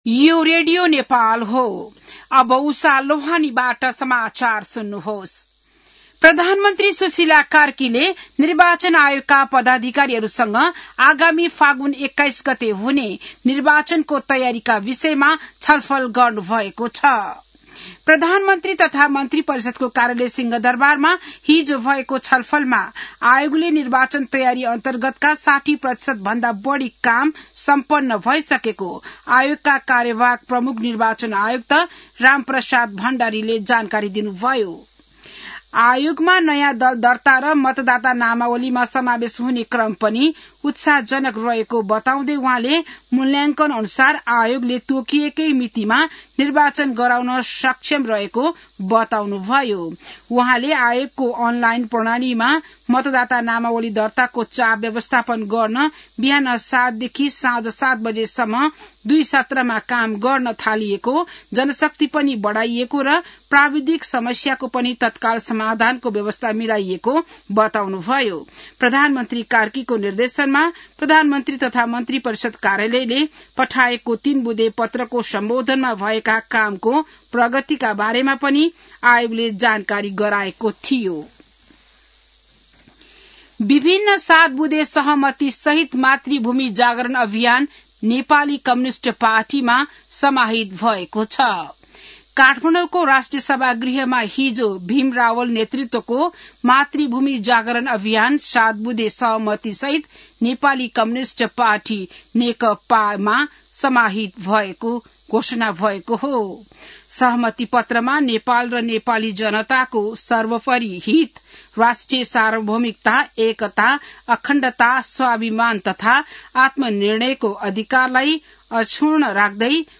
बिहान १० बजेको नेपाली समाचार : २७ कार्तिक , २०८२